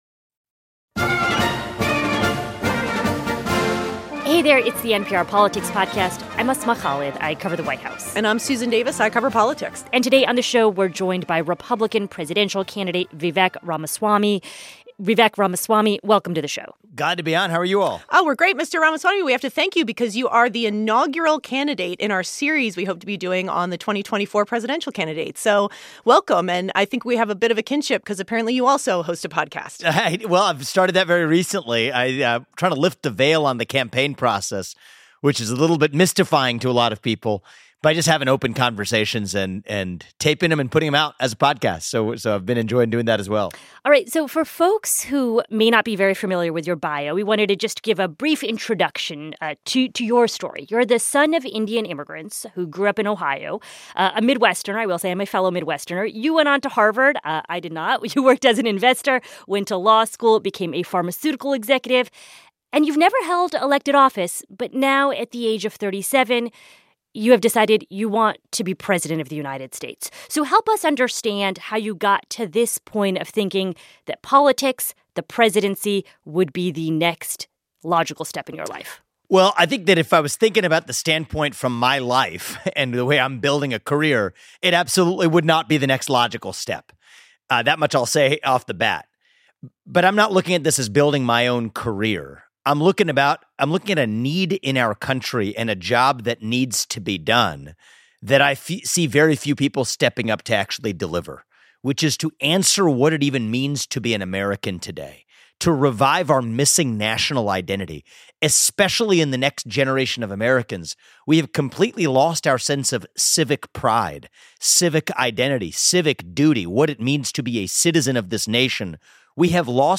Our Interview With GOP Presidential Hopeful Vivek Ramaswamy